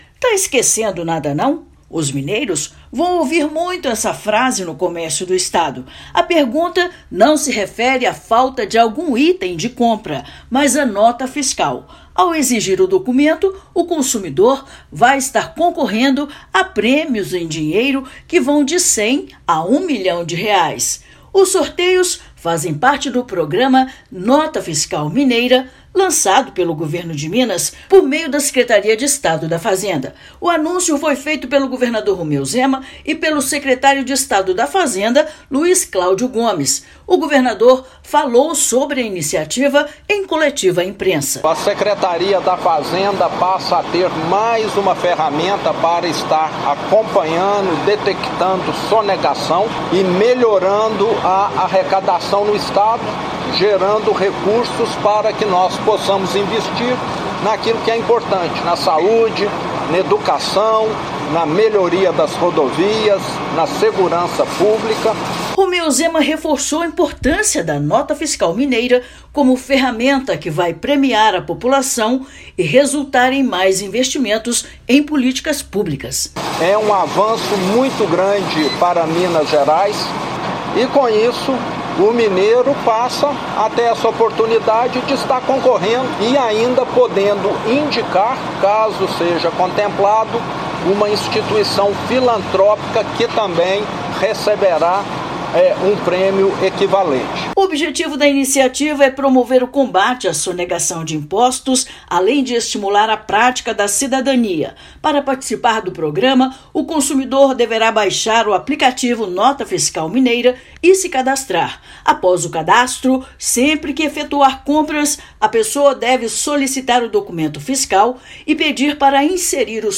Para participar, basta baixar o aplicativo e solicitar o CPF na nota de compras de qualquer valor; todas as regiões do estado são contempladas. Ouça matéria de rádio.